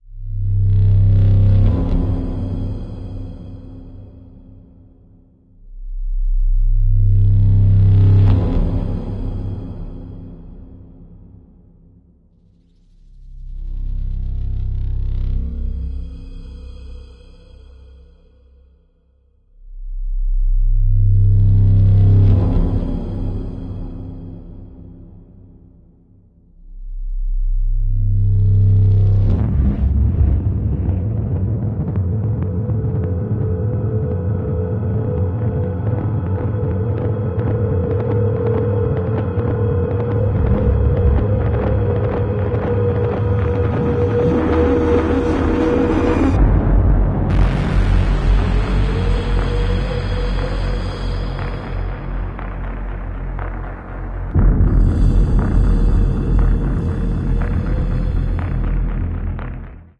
Tenet breathing audio Should Capcut Let Me Make Sound Effects Free Download.